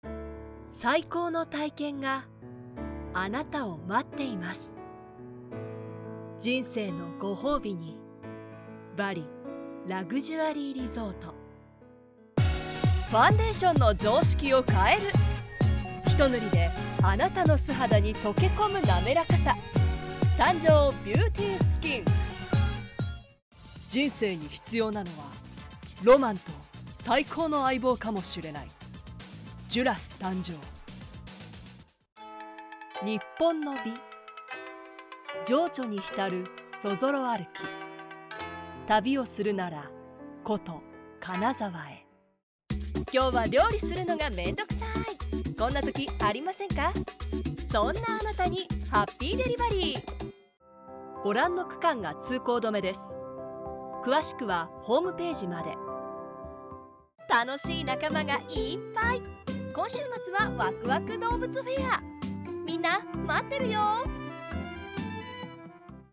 Japanese voice over, Japanese voice, Japanese commercial, corporate, business, documentary, e-learning, product introductions
Sprechprobe: Werbung (Muttersprache):
CM（BGM）.mp3